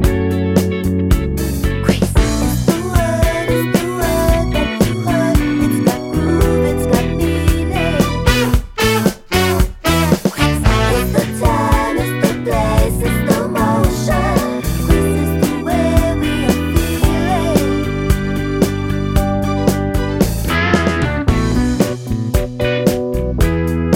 no Backing Vocals Soundtracks 3:12 Buy £1.50